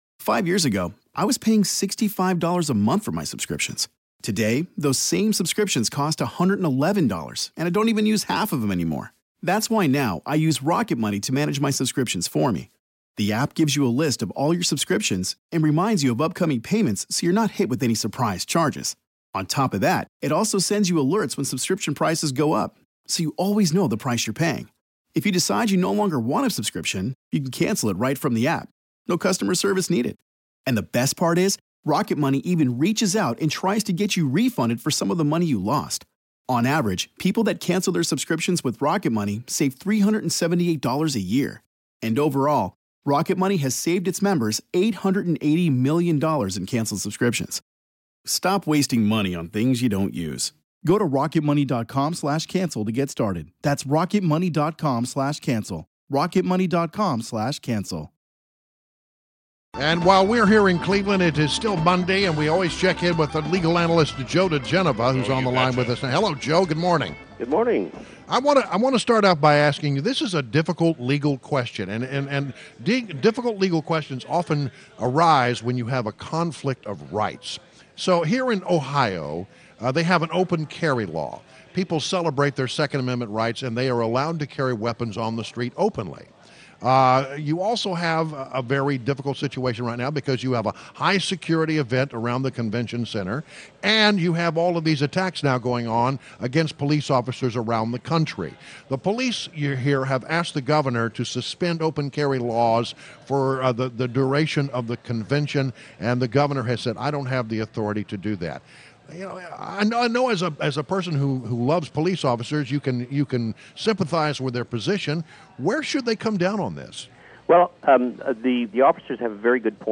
WMAL Interview